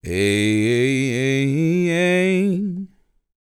MOANIN 104.wav